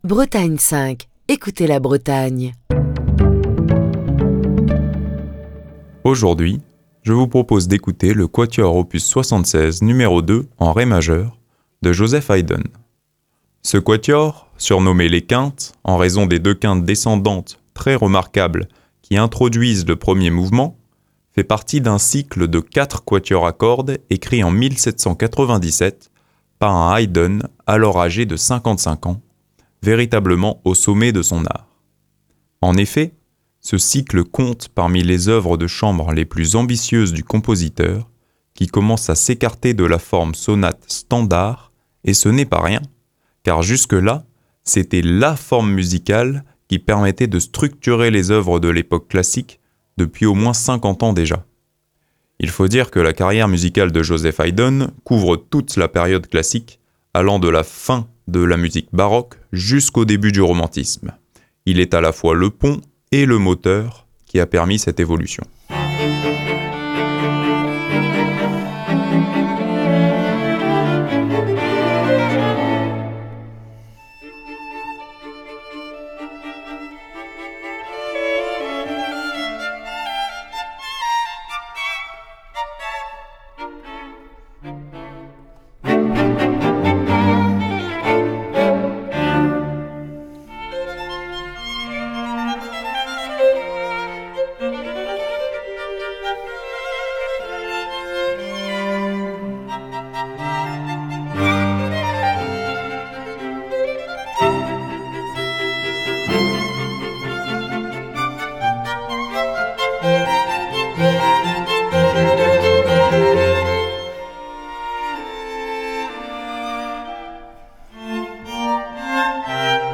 quatuors à cordes
avec ses fameuses quintes descendantes pleine d'expressivité
avec au violon un chant espiègle et ciselé